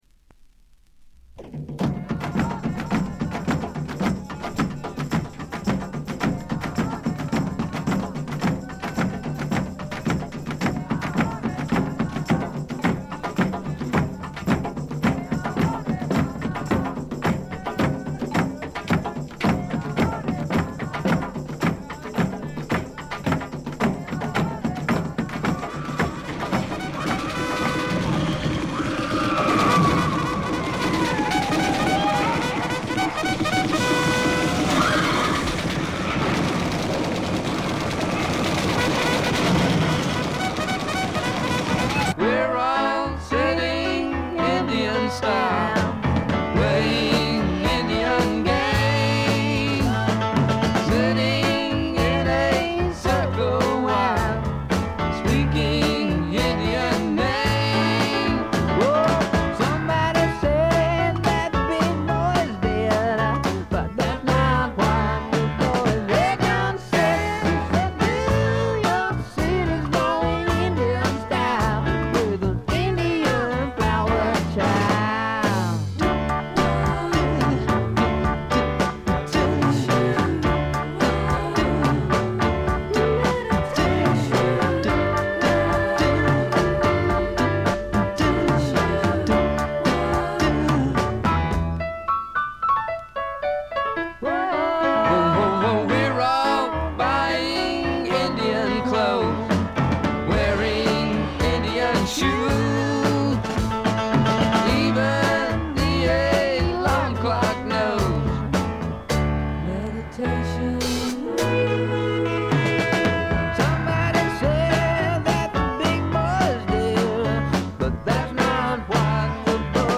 軽微なバックグラウンドノイズ、少しチリプチ。
60年代ポップ・サイケな色彩でいろどられたサージェント・ペパーズな名作！！
試聴曲は現品からの取り込み音源です。